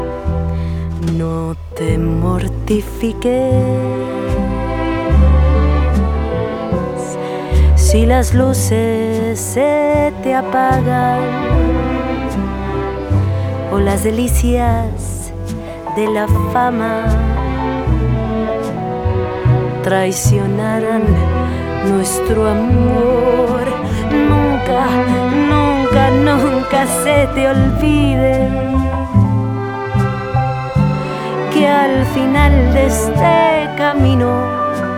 Latin Cuban Bolero